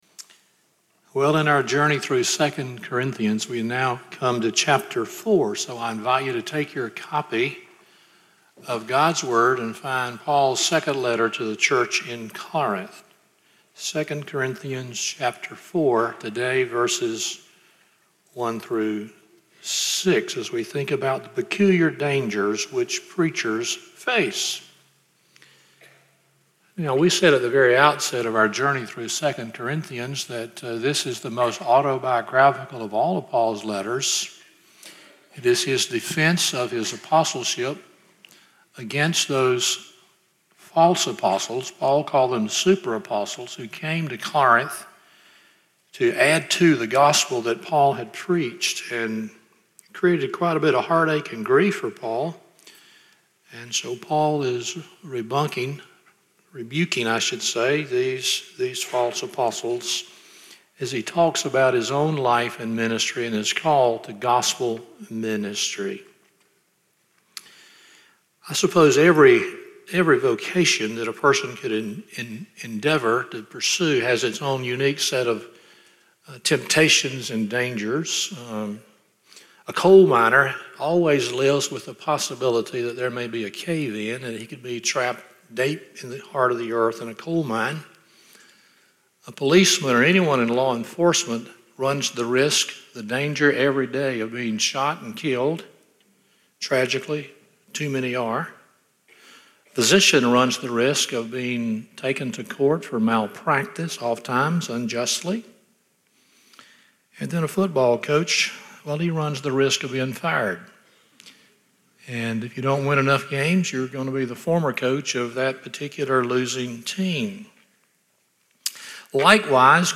2 Corinthians 4:1-6 Service Type: Sunday Morning 1.